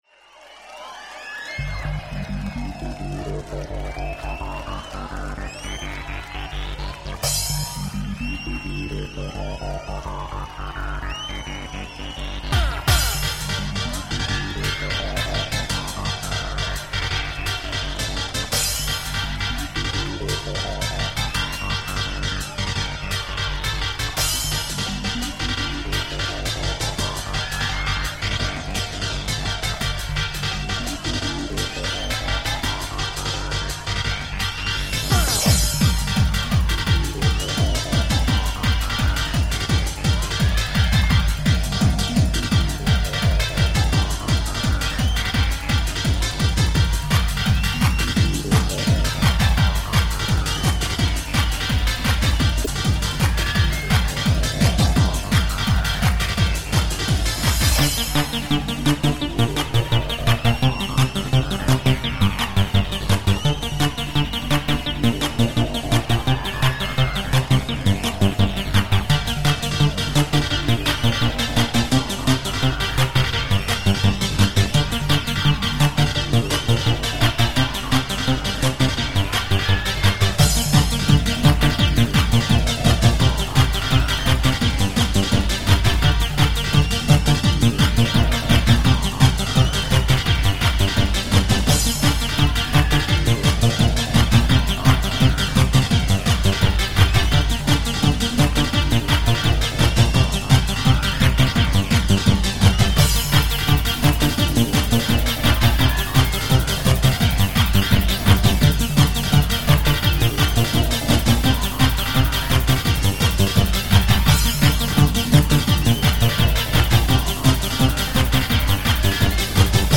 [mix]
• Quality: 44kHz, Stereo